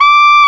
SAX TENORF1Z.wav